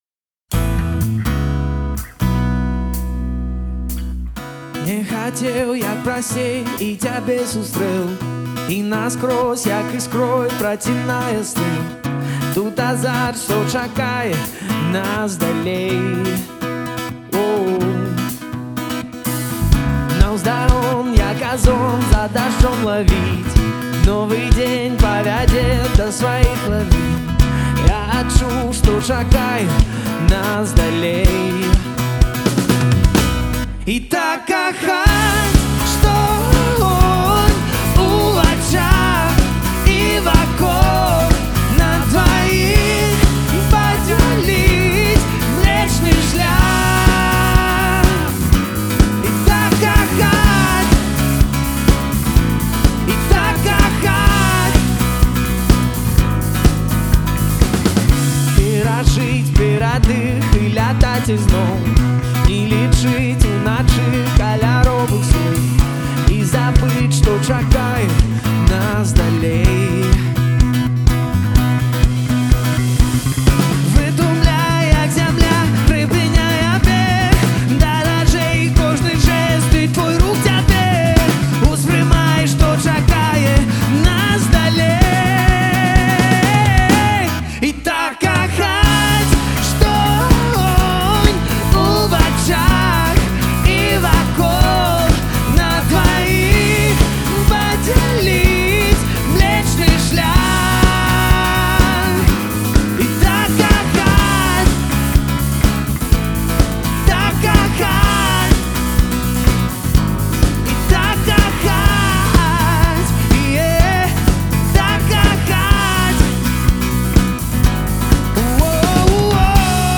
запісаны жыўцом у студыі праграмы